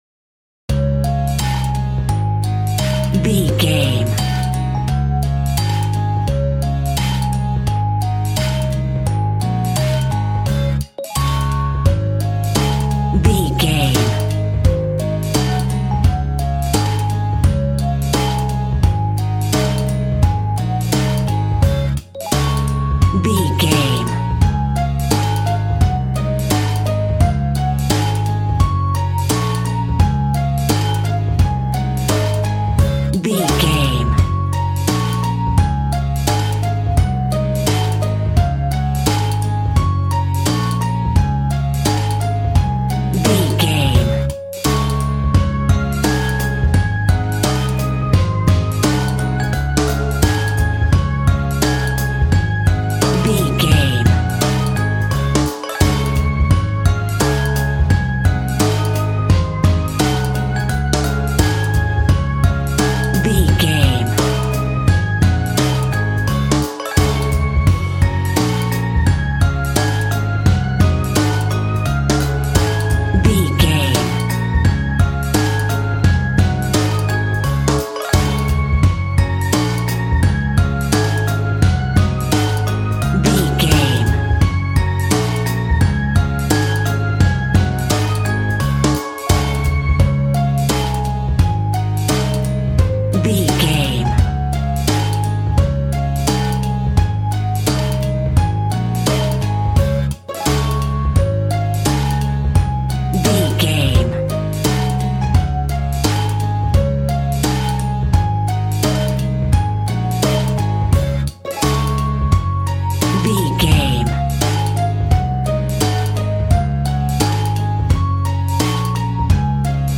Ionian/Major
D♭
childrens music
instrumentals
fun
childlike
cute
happy
kids piano